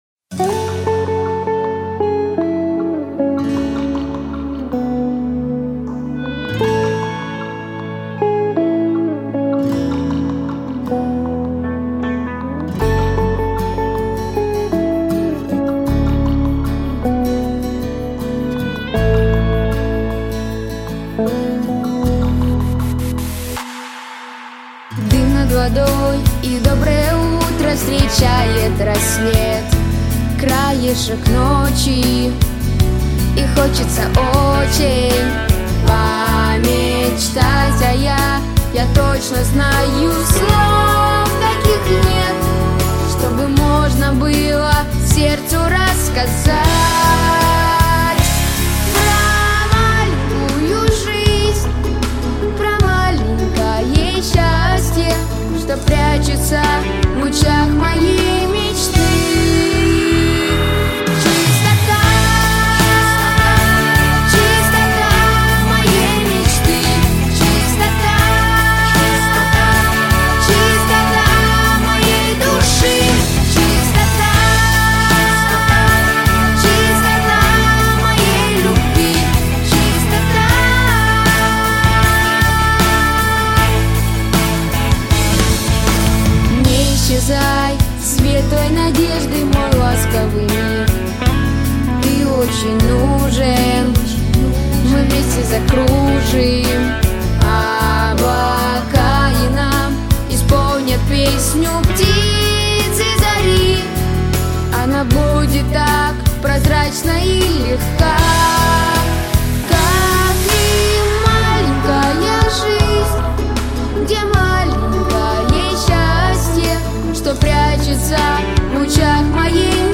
• Качество: Хорошее
• Категория: Детские песни